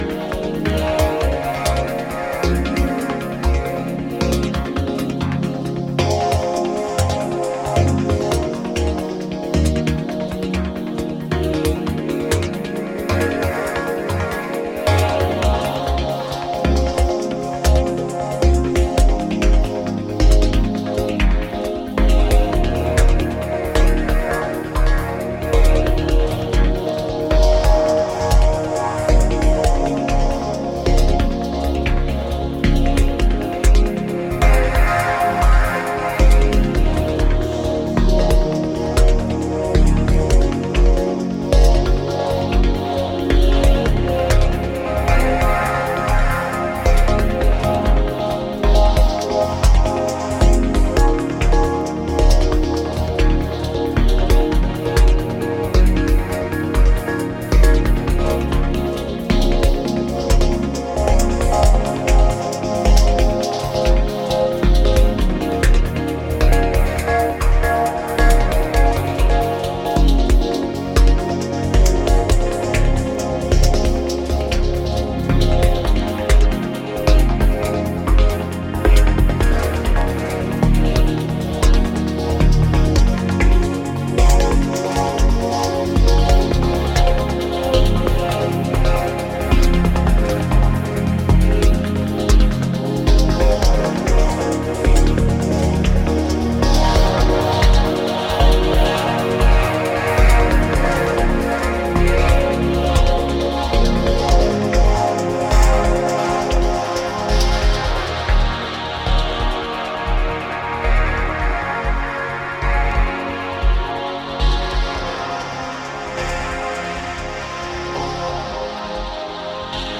なんといっても殆どキックが入らないトランシーでエクスタティックな構成がたまらないです。